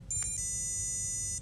Bekreftelseslyden er:"di-di-daaaa"